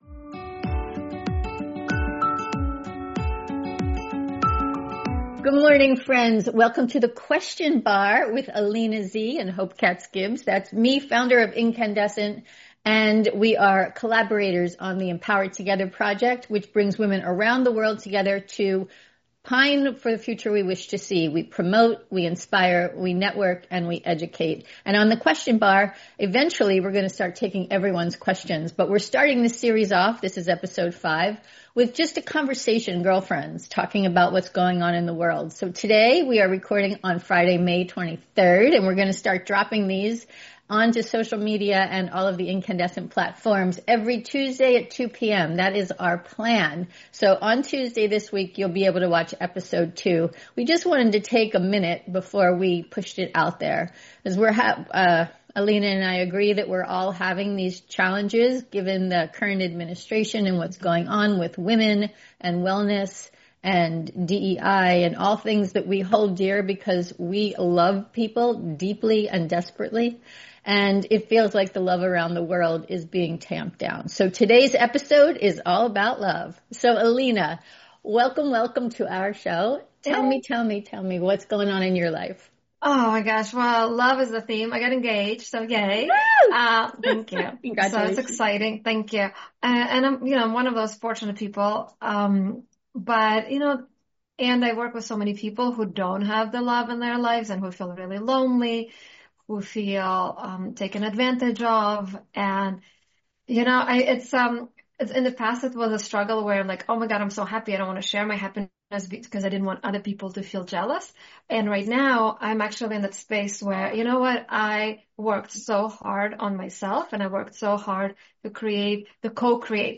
invites you in to listen in on a chat with two girlfriends talking about topics of the day